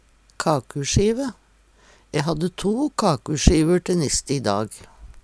kakusjive - Numedalsmål (en-US)